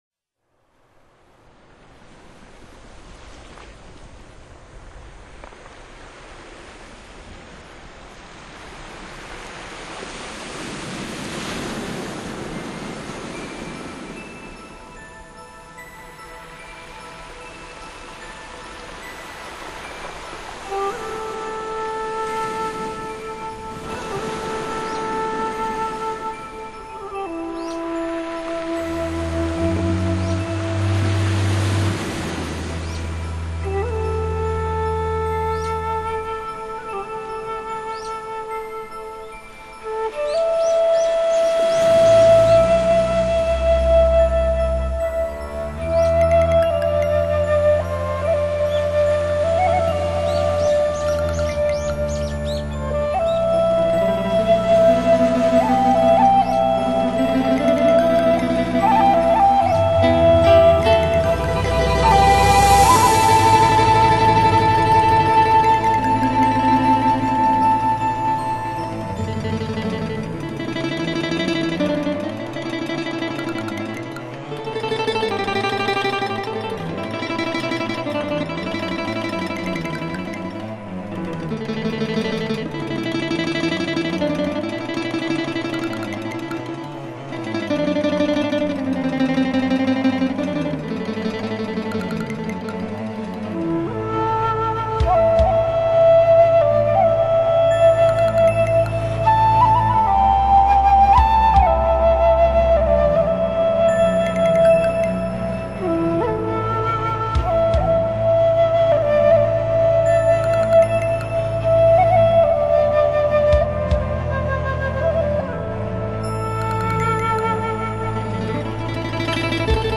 10首畫樂精選，以音符跳起躍下的自由散拍，讓畫樂成為最奇特的聲色美學。